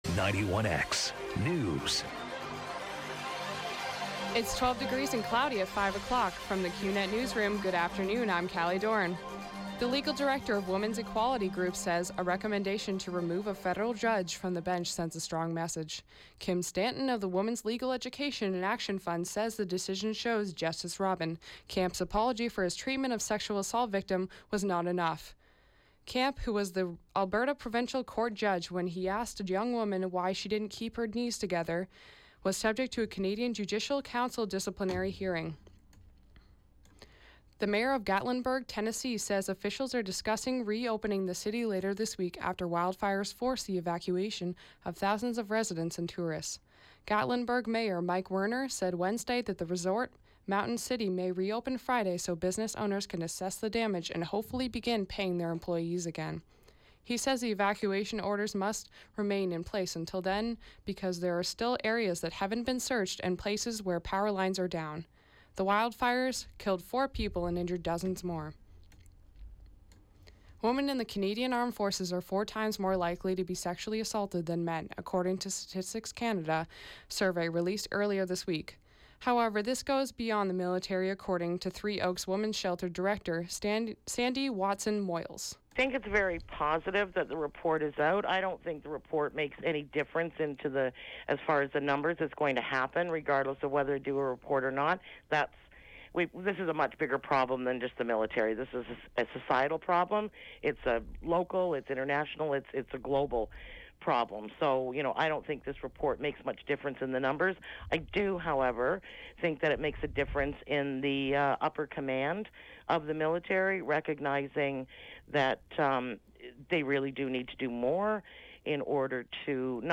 91X FM Newscast – Wednesday, Nov. 30, 2016, 5 p.m.